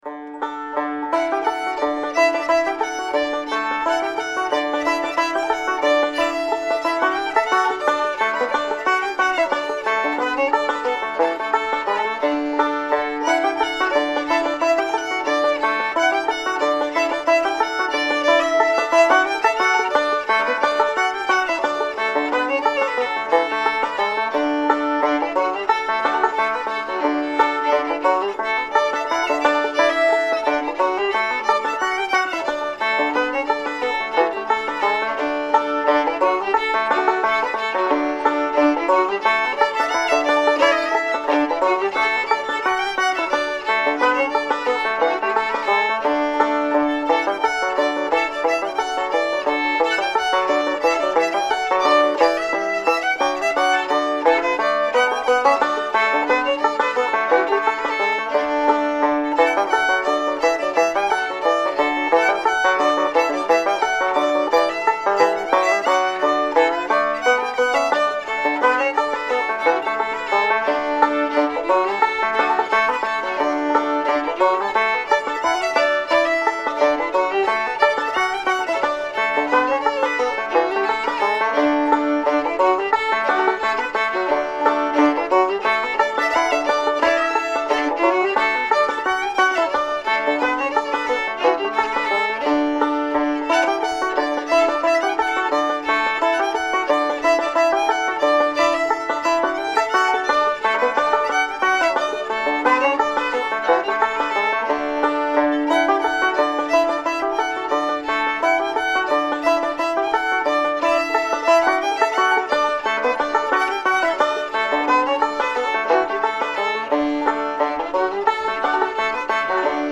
Thursday Jam Session
NEW TUNES: KEY OF D